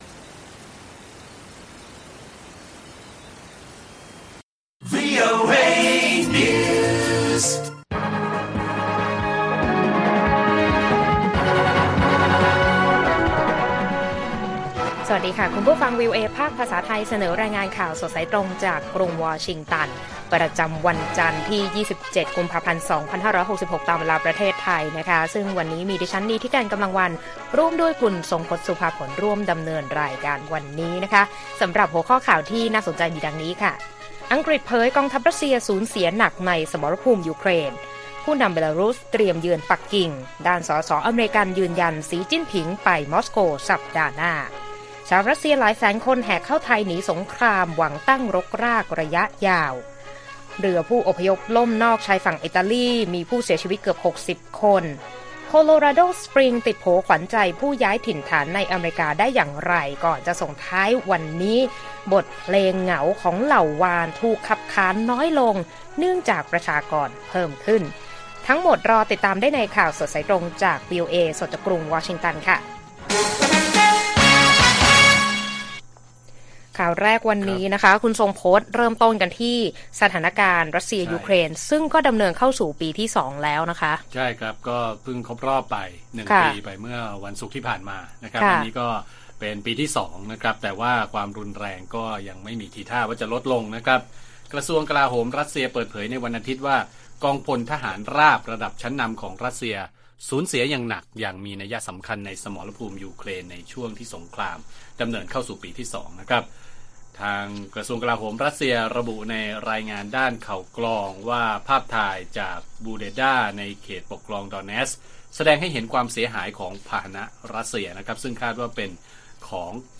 ข่าวสดสายตรงจากวีโอเอไทย 8:30–9:00 น. 27 ก.พ. 2566